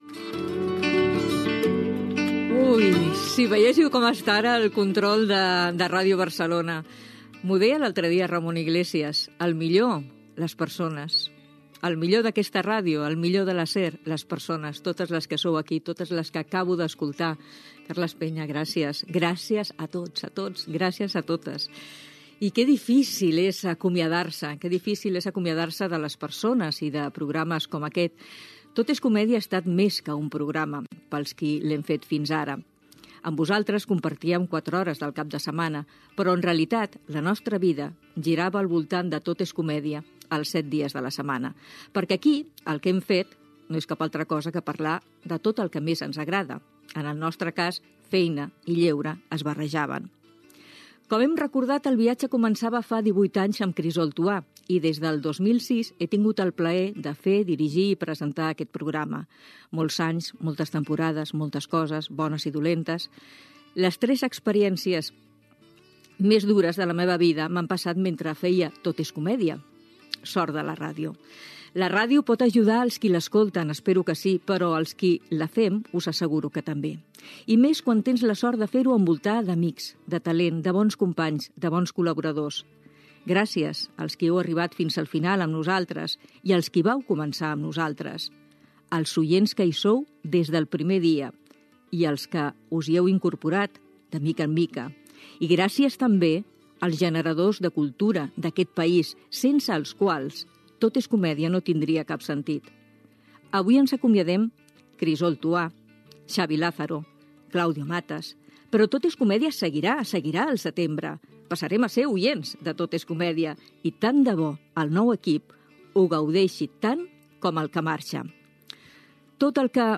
Indicatiu del programa, publicitat, promo web i app de SER Catalunya i de "Llapis de memòria".